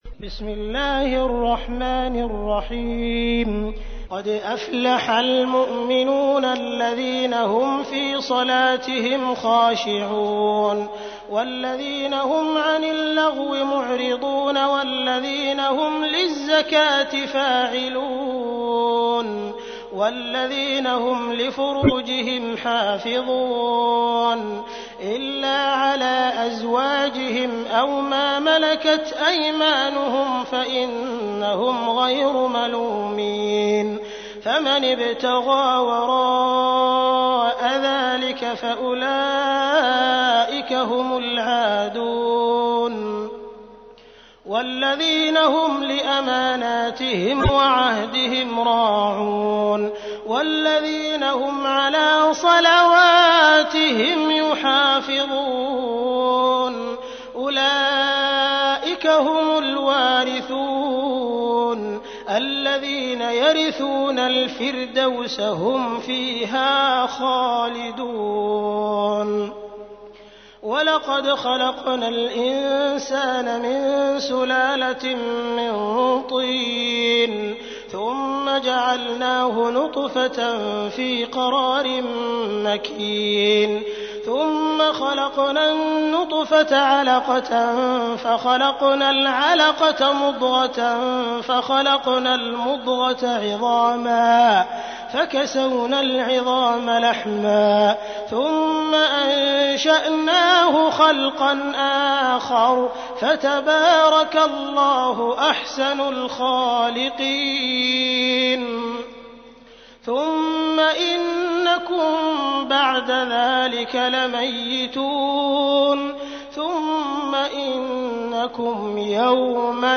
تحميل : 23. سورة المؤمنون / القارئ عبد الرحمن السديس / القرآن الكريم / موقع يا حسين